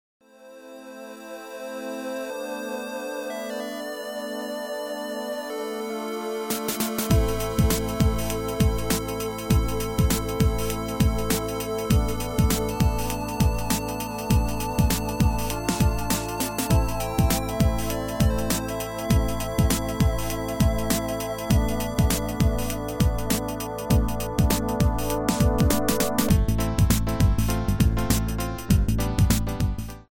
Trilha Sonora